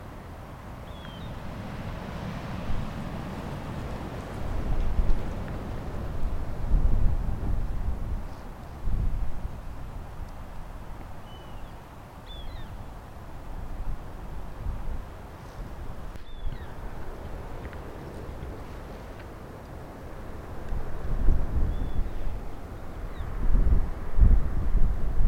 6. Northern Harrier (Circus hudsonius)
Call: A series of “kek-kek-kek” during breeding season.